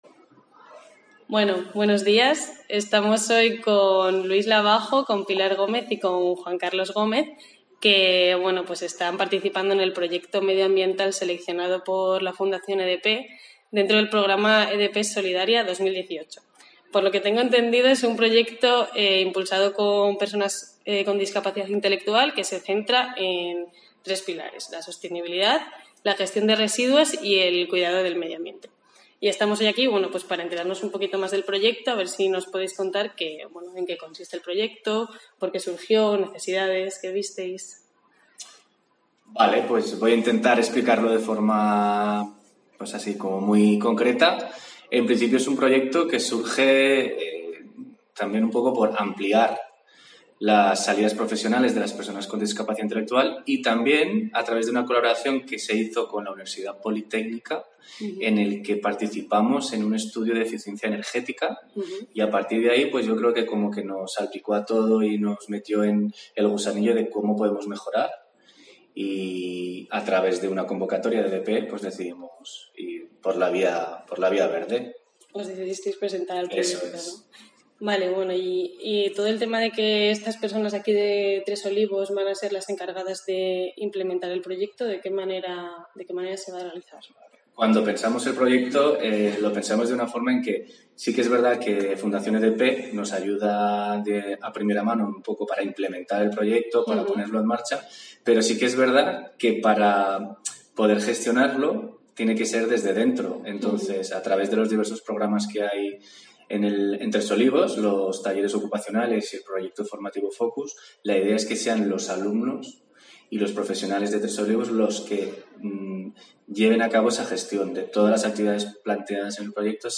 Down Madrid » Entrevista de Down Madrid al equipo de Emprendimiento Medioambiental
edp-entrevista.mp3